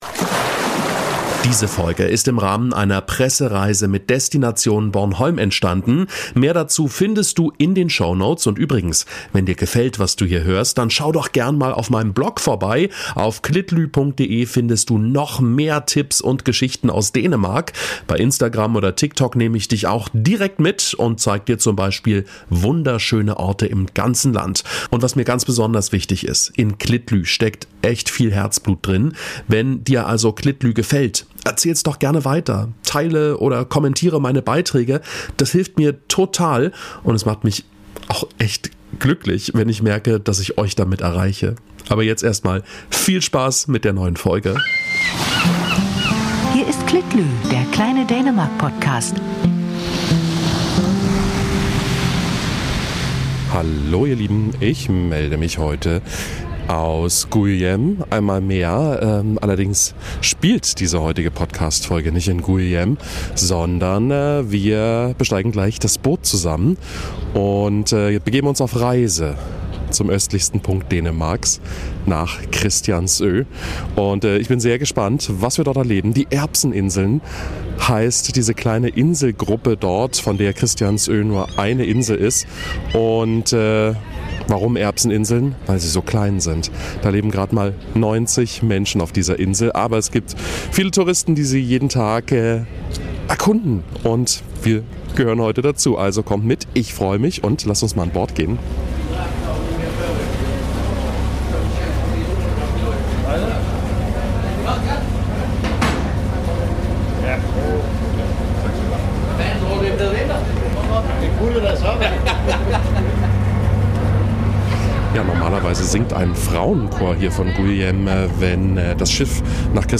Veteran, entrepreneur and billionaire business leader Bob Parsons